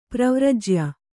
♪ pravrajya